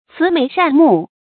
慈眉善目 注音： ㄘㄧˊ ㄇㄟˊ ㄕㄢˋ ㄇㄨˋ 讀音讀法： 意思解釋： 形容人的容貌一副善良的樣子。